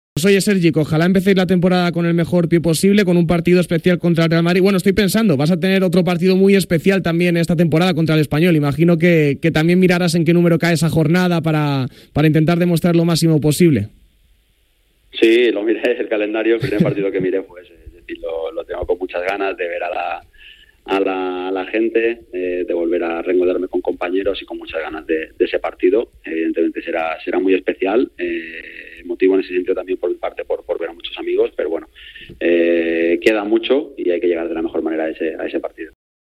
Una circunstancia que no ha pasado desapercibida para el balear, que esta mañana, en entrevista al programa de ‘Radio Marca’ ‘A diario’ ha sido preguntado por ese hecho; el de Artà, que ha des